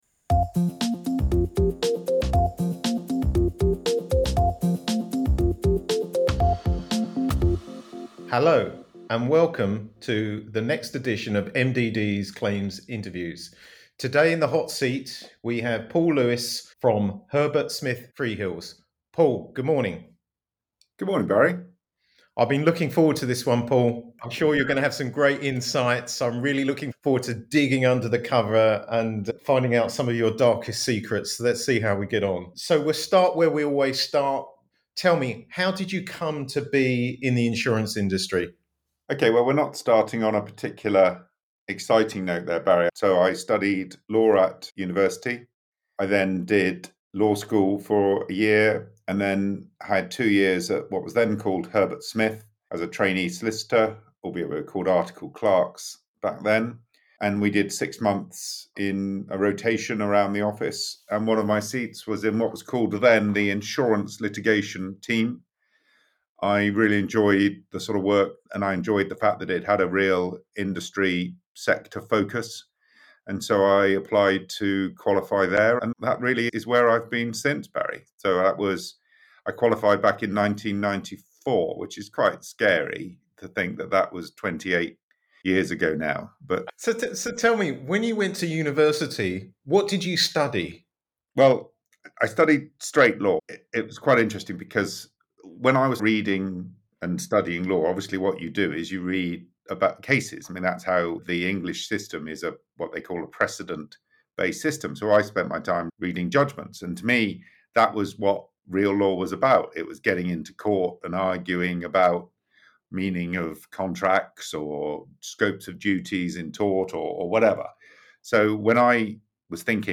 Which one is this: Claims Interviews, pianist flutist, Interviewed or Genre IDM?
Claims Interviews